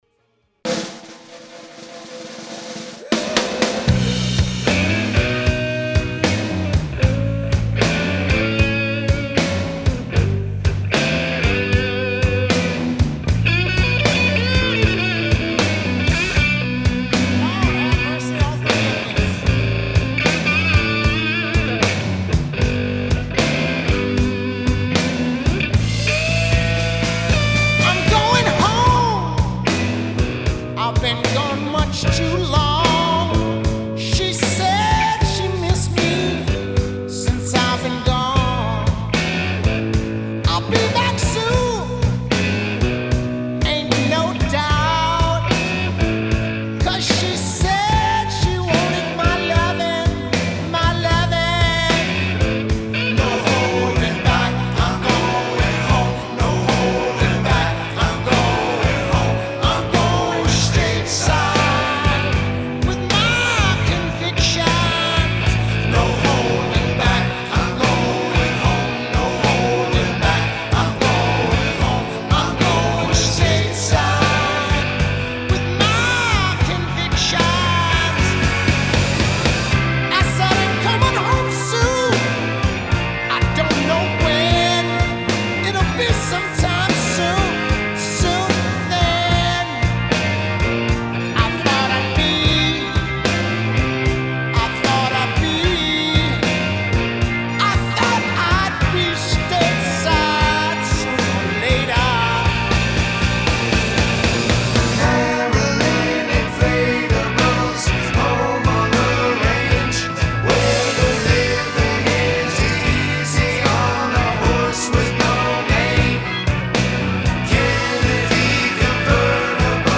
it had a much harder edge to it.